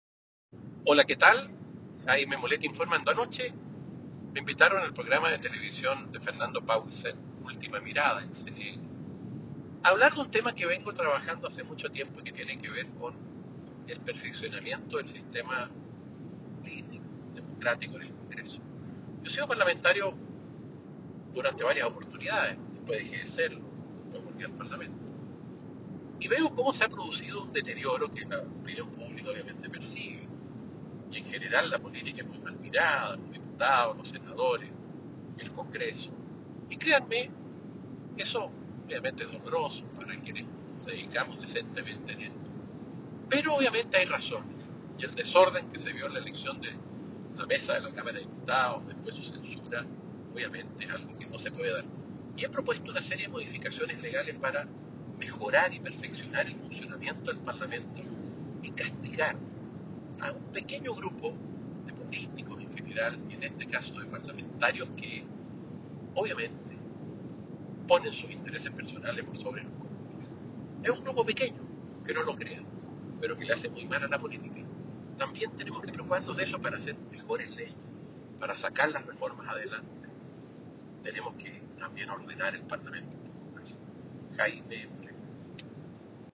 Audio Diputado Jaime Mulet , refiriéndose al proyecto que busca actualizar el sistema político.